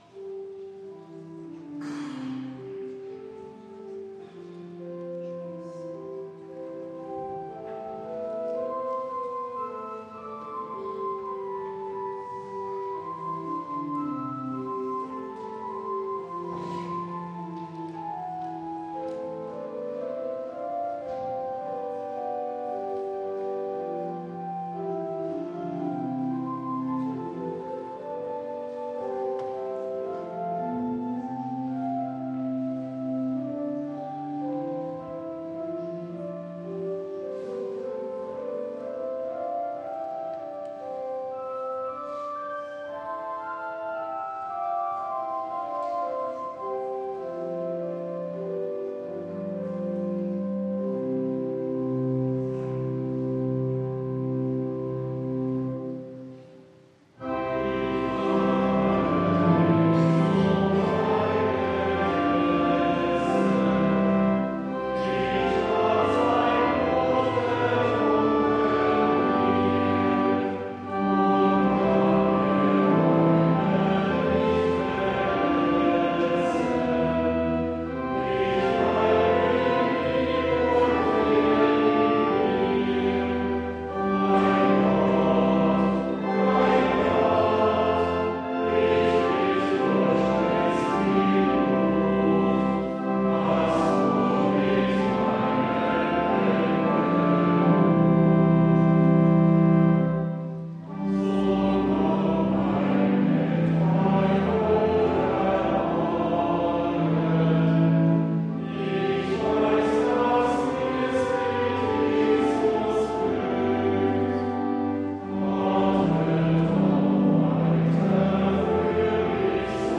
Audiomitschnitt unseres Gottesdienstes vom Sonntag Lätare 2024